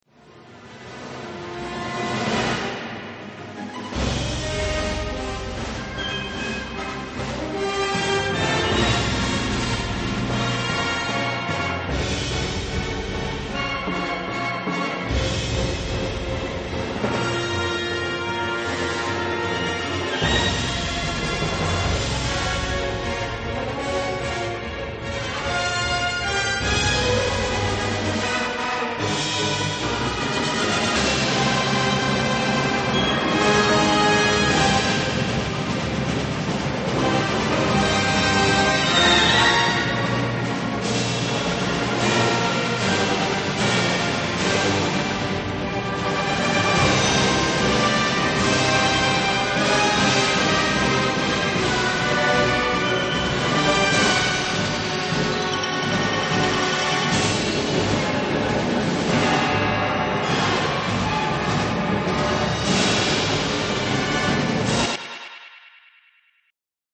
想像を絶するダイナミックなスコアになりました。